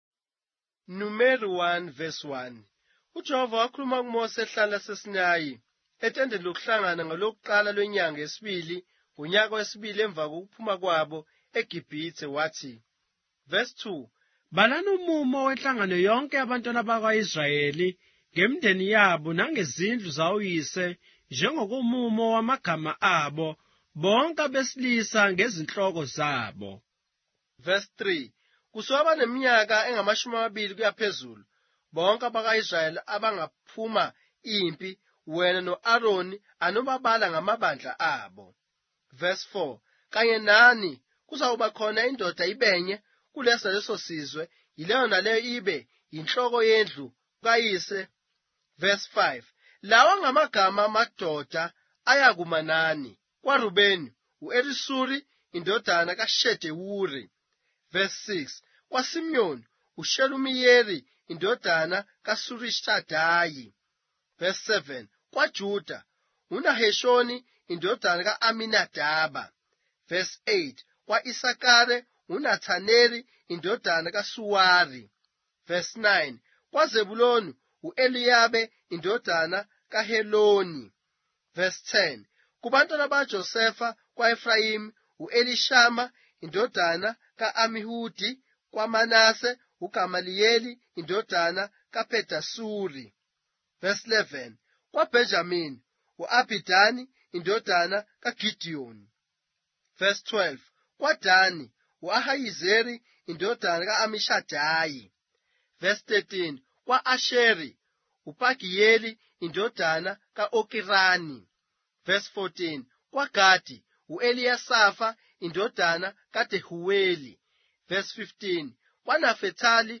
Numbers, chapter 1 of the Zulu Bible, with audio narration